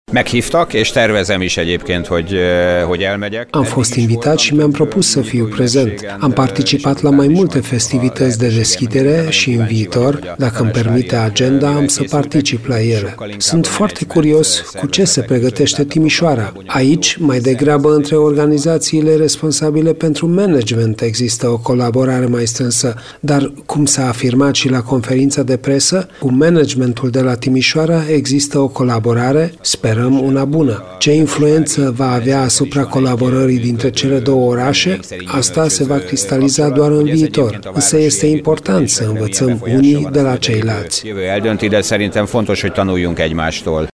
Asociația Timișoara 2023 colaborează cu organizația similară din Veszprem, iar primarul municipiului din Ungaria, Gyula Porga, este curios de festivitatea de la Timișoara.